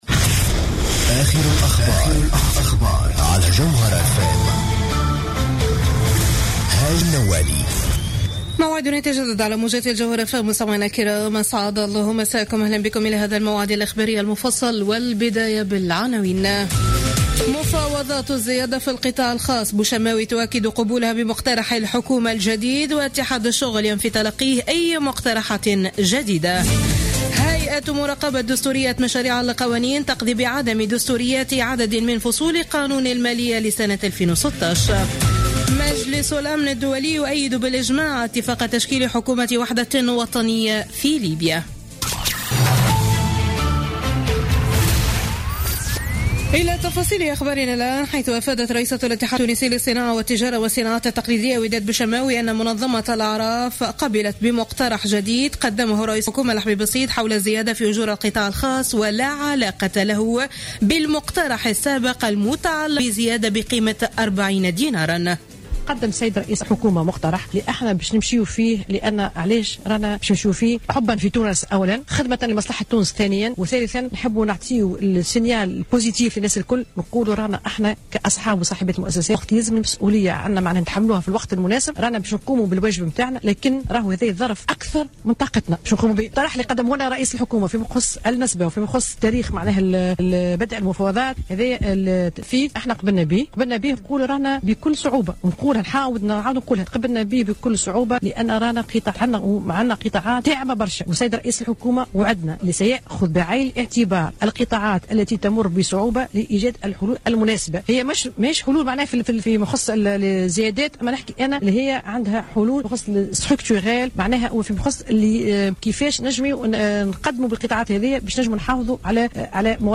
نشرة أخبار منتصف الليل ليوم الخميس 24 ديسمبر 2015